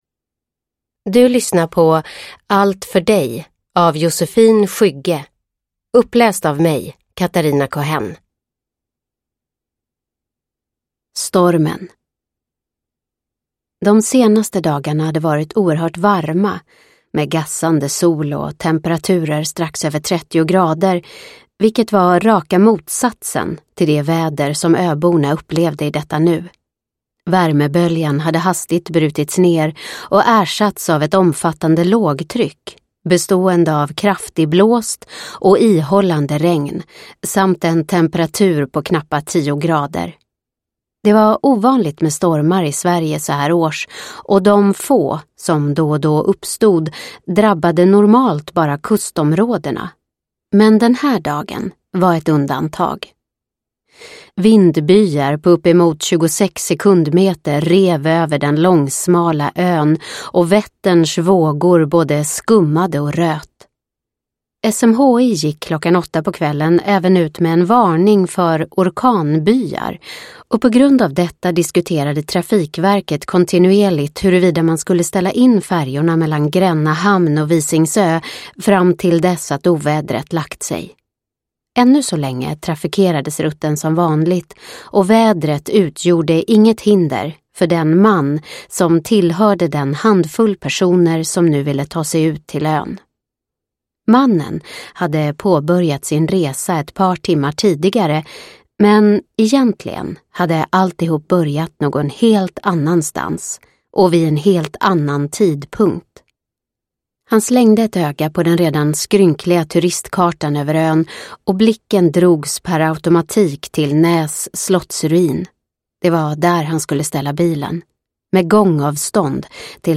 Allt för dig – Ljudbok – Laddas ner